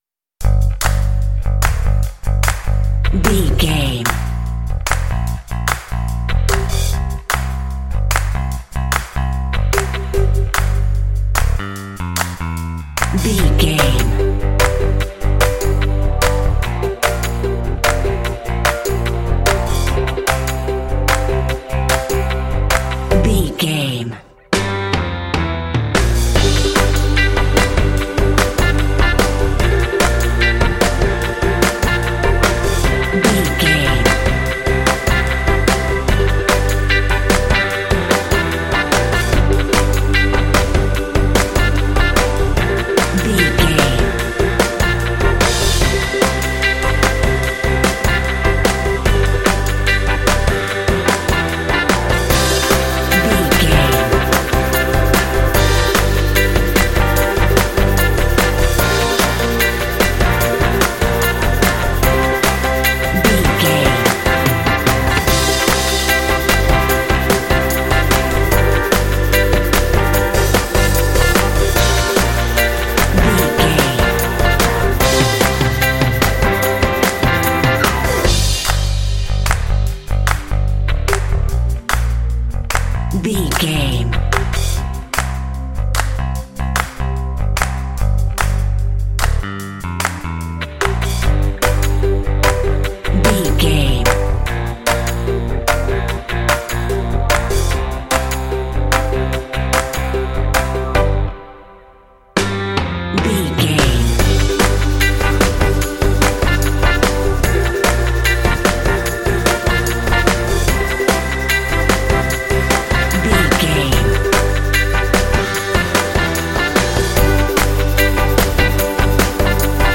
Aeolian/Minor
fun
bright
lively
sweet
brass
horns
electric organ
drums
bass guitar
modern jazz